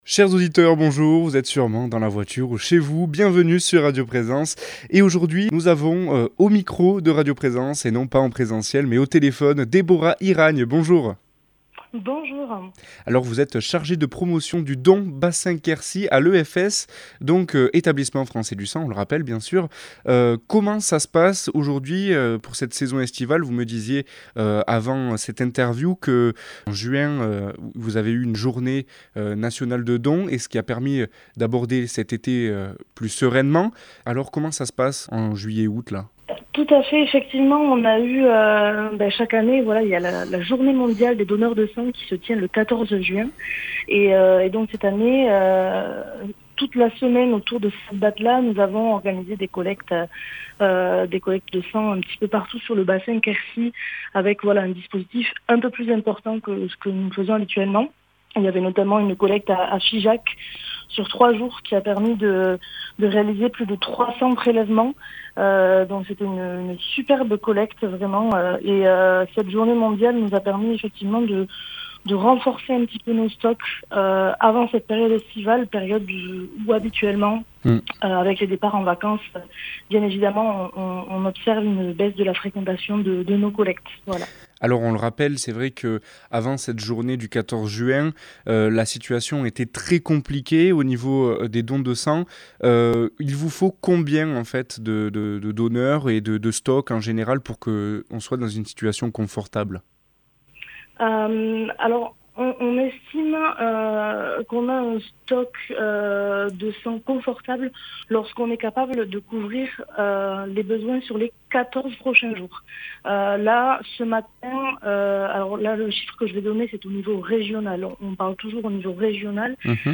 efs pad interview
efs_pad_interview.mp3